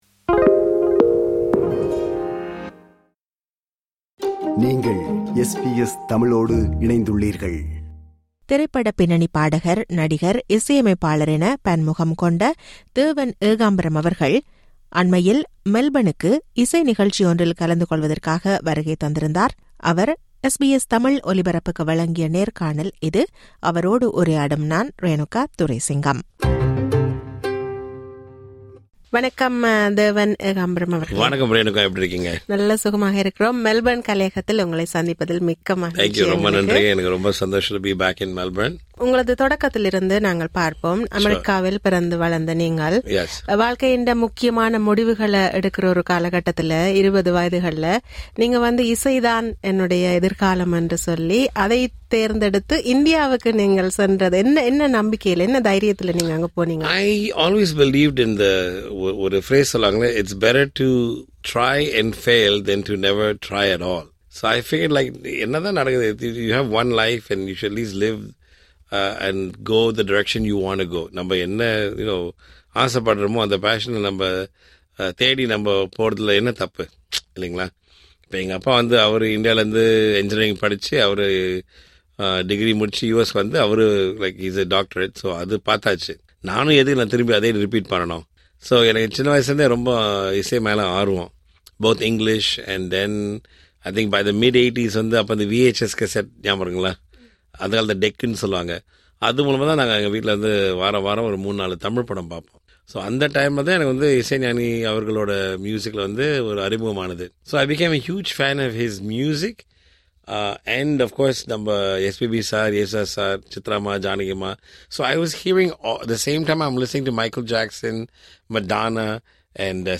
திரைப்பட பின்னணி பாடகர், இசையமைப்பாளர், நடிகர் என பன்முகம் கொண்ட தேவன் ஏகாம்பரம் அவர்கள் அண்மையில் மெல்பன் வருகை தந்திருந்தார். அவரை SBS மெல்பன் கலையகத்தில் சந்தித்து உரையாடுகிறார்